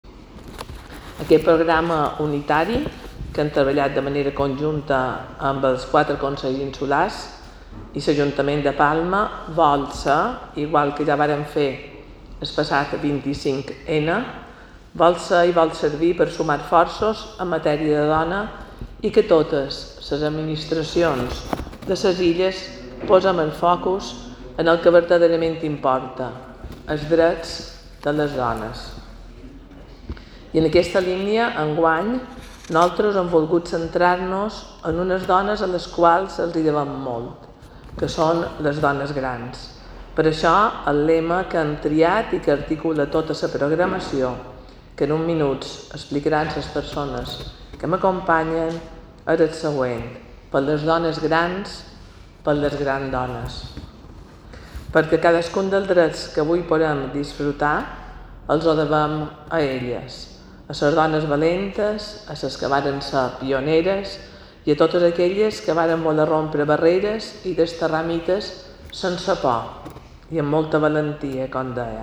Declaraciones de la consellera Catalina Cirer